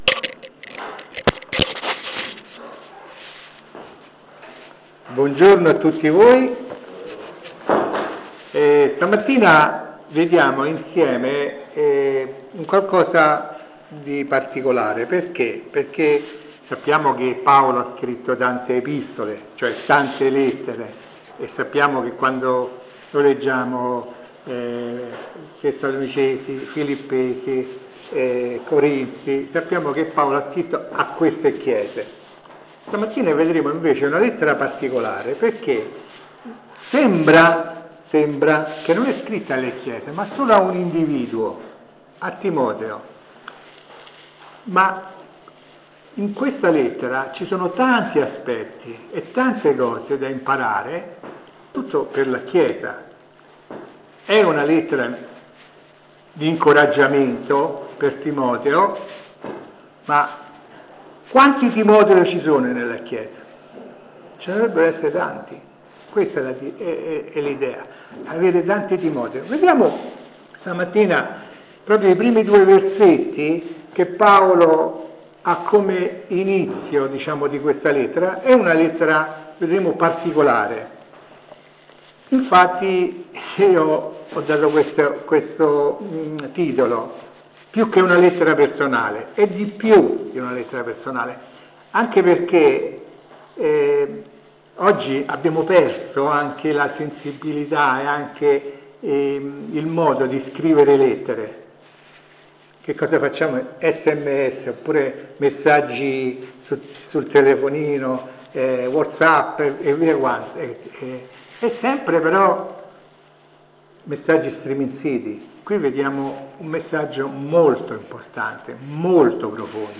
Insegnamenti biblici sul passo di 1 Timoteo 1:1-2.